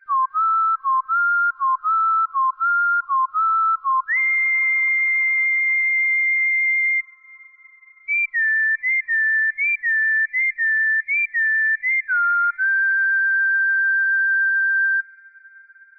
synth-57.wav